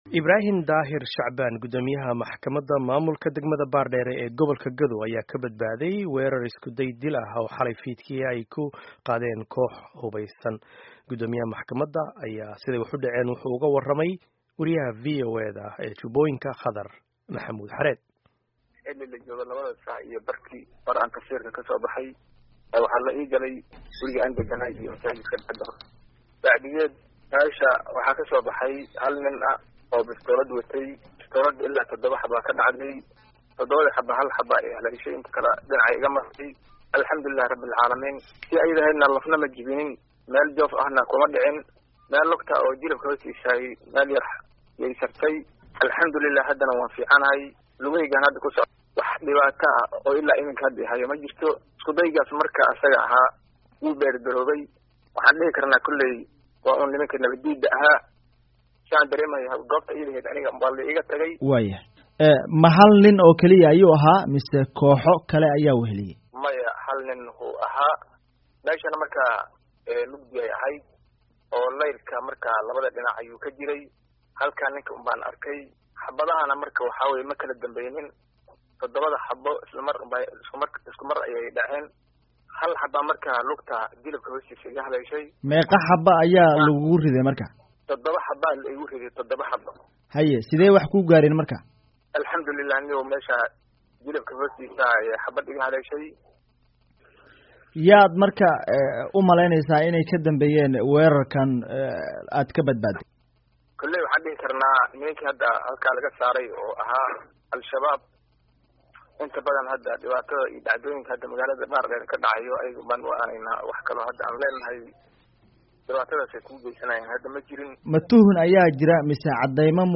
Dhageyso Wareysiga Gud. Maxkamaded oo Dil ka badbaadey